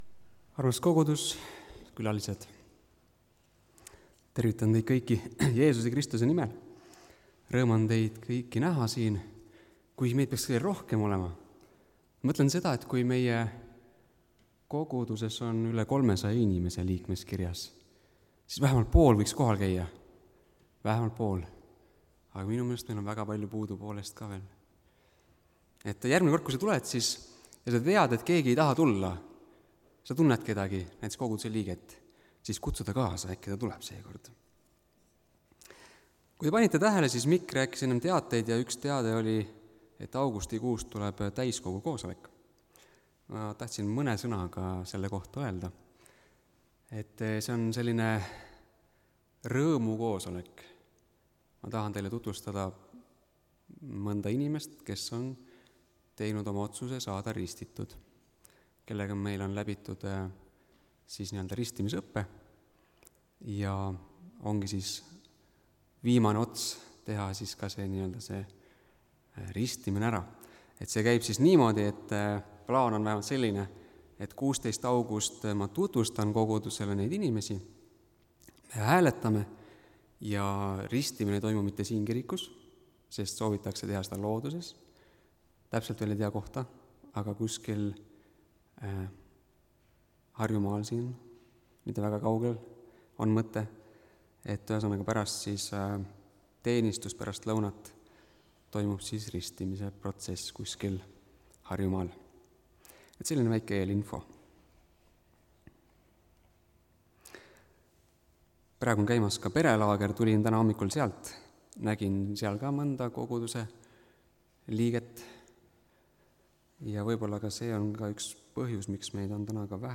(Tallinnas)
Jutlused